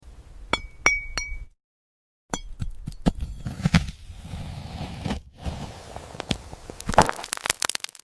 ・0–2s: Complete silence, camera holds steady on the glowing fruit. ・2s: Knife tip touches surface → high-pitched “tink” (glass-like). ・2–4s: Knife slices downward → long, sizzling “shhhk” with a faint crackle (like hot coal). ・4–6s: The orange splits open → thick molten lava oozes with bubbling “blup-blup” + subtle steam hiss. ・6–8s: Tiny glowing sparks rise into the air, fading with a faint “tinkle” chime.